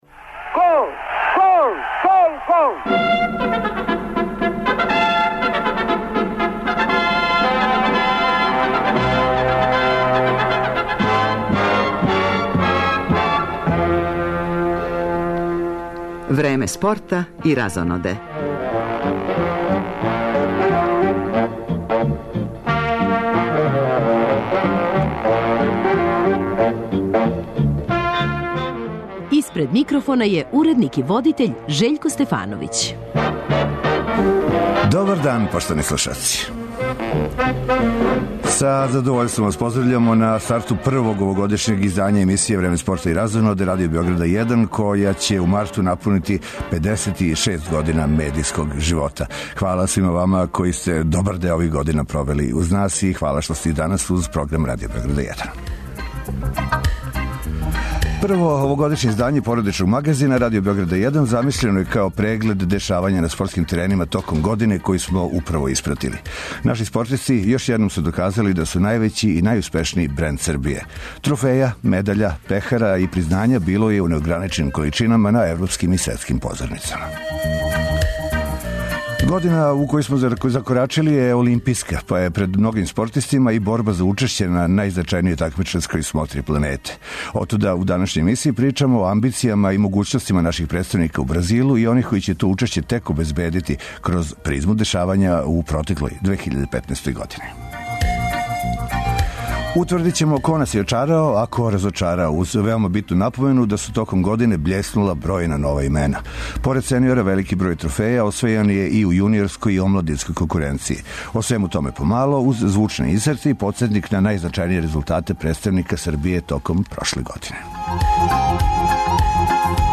О свему томе по мало, уз звучне инсерте и подсетник на најзначајније резултате представника Србије током прошле године.